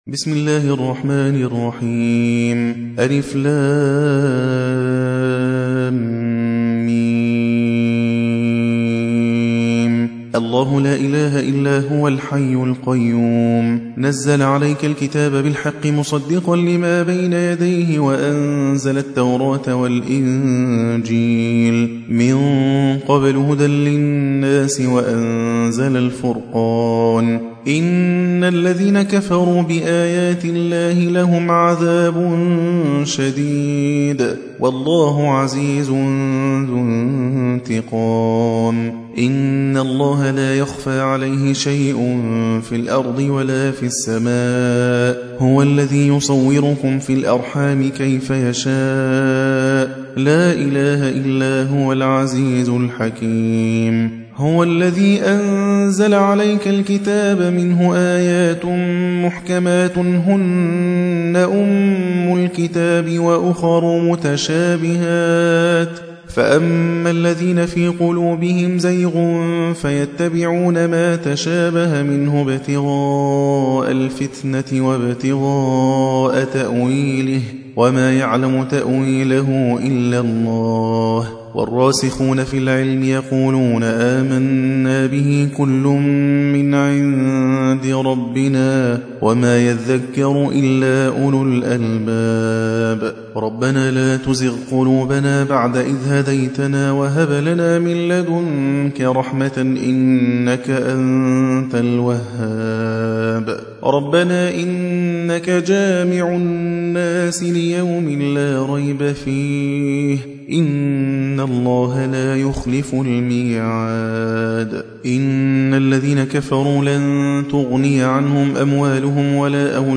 3. سورة آل عمران / القارئ